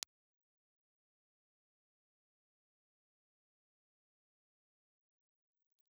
Impulse Response file of STC 4033A microphone in position P
STC4033_Pressure_IR.wav
• P = Pressure (omnidirectional, dynamic element only)